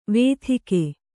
♪ vēthike